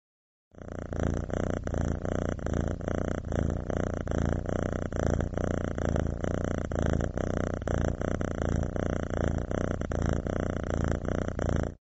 purring-cat.mp3